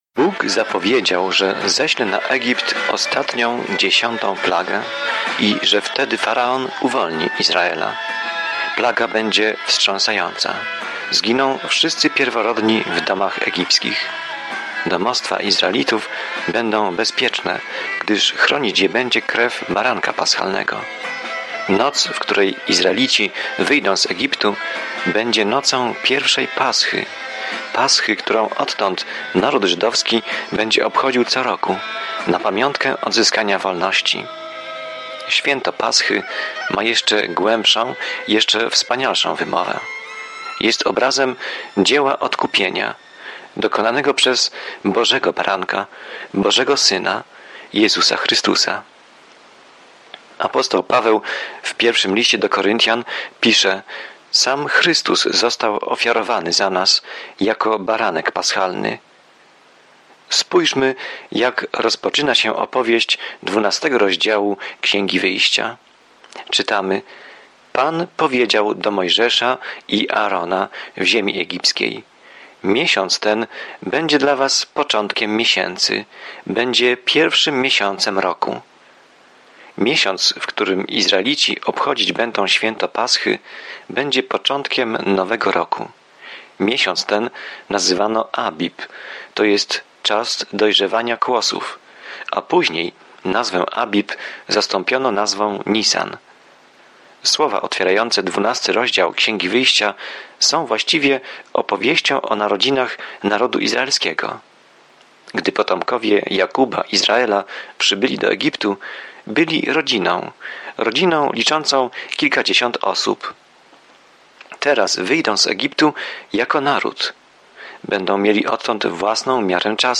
Pismo Święte Wyjścia 12 Dzień 7 Rozpocznij ten plan Dzień 9 O tym planie Exodus śledzi ucieczkę Izraela z niewoli w Egipcie i opisuje wszystko, co wydarzyło się po drodze. Codzienna podróż przez Exodus, słuchanie studium audio i czytanie wybranych wersetów słowa Bożego.